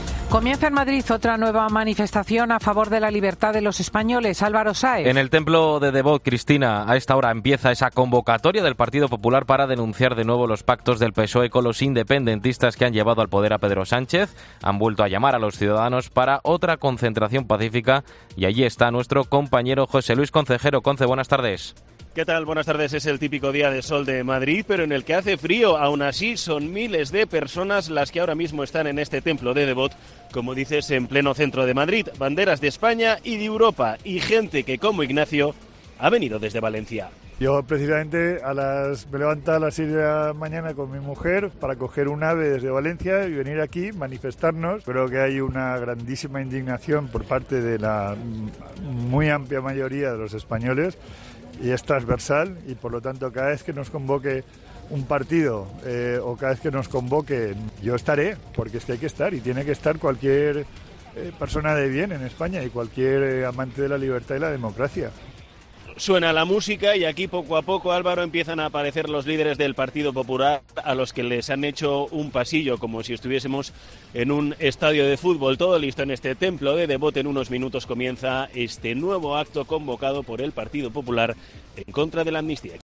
informa sobre la manifestación contra la amnistía convocada en el templo de Debod